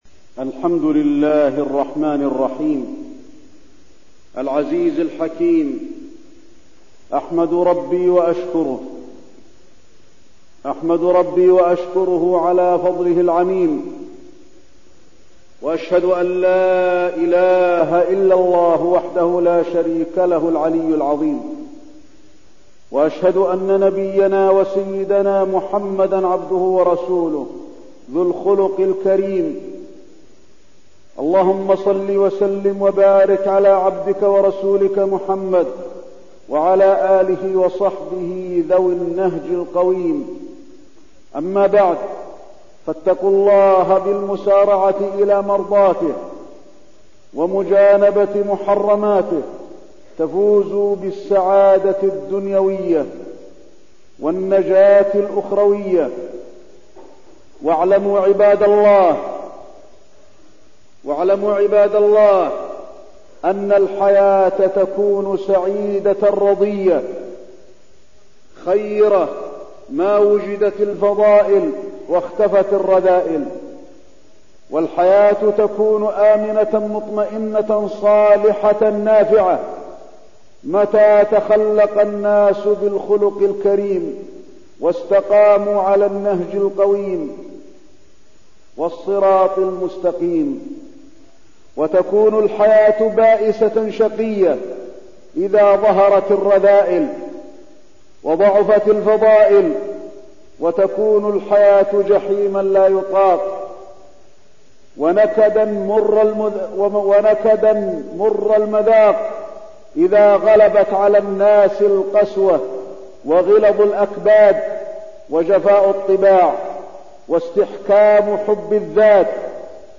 تاريخ النشر ١٠ جمادى الآخرة ١٤١٣ هـ المكان: المسجد النبوي الشيخ: فضيلة الشيخ د. علي بن عبدالرحمن الحذيفي فضيلة الشيخ د. علي بن عبدالرحمن الحذيفي الرحمة